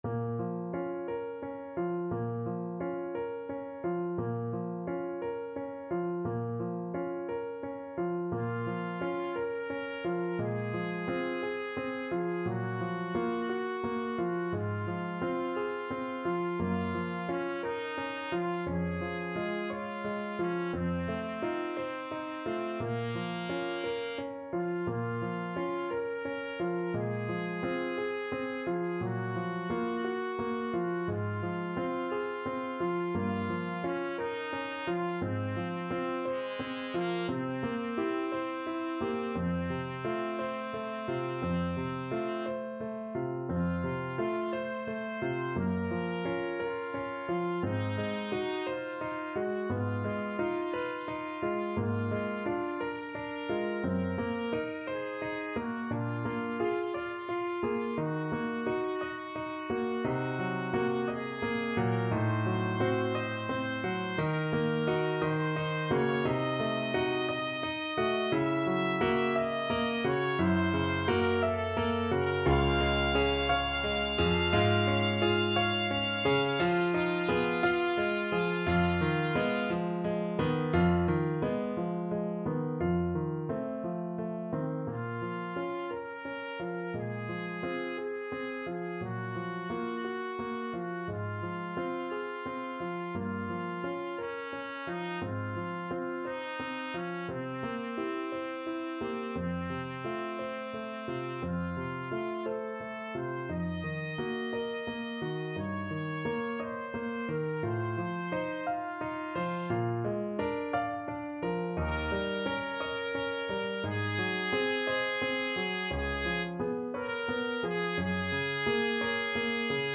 Trumpet version
Classical Trumpet